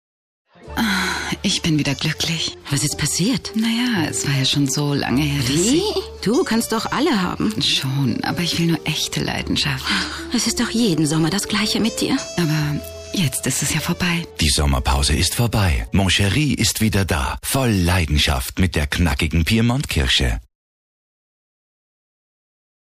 Sprecherin türkisch, Schauspielerin.
Sprechprobe: Industrie (Muttersprache):
turkish female voice over artist.